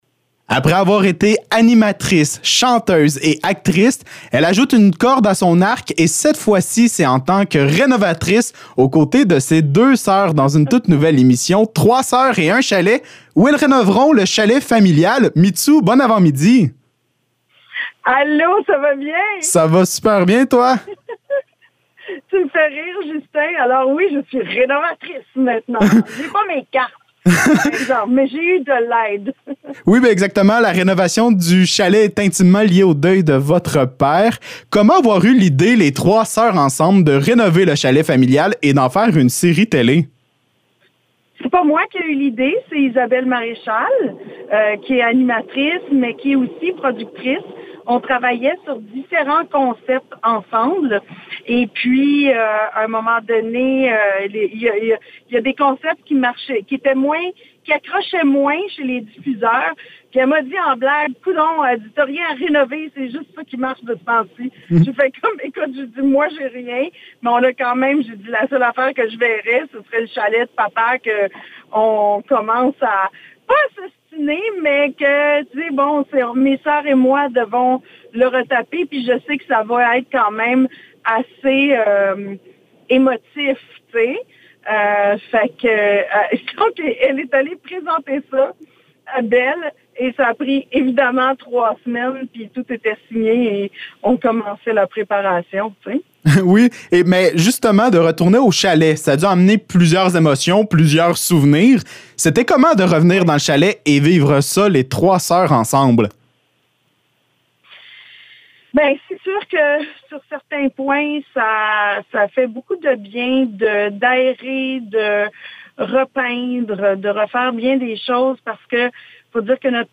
Entrevue avec Mitsou